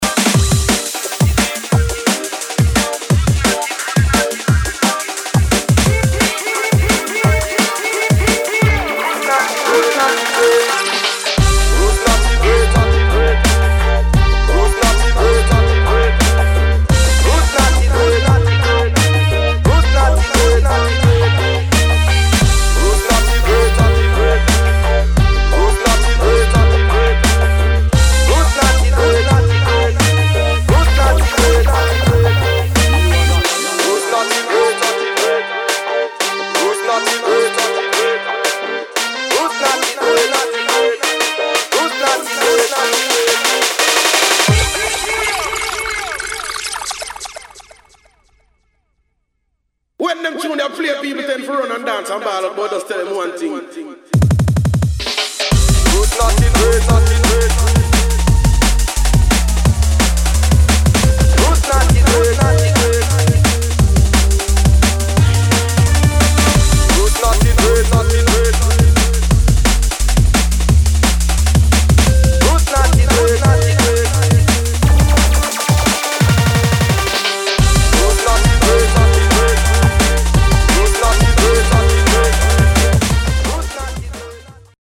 DRUM'N'BASS / JUNGLE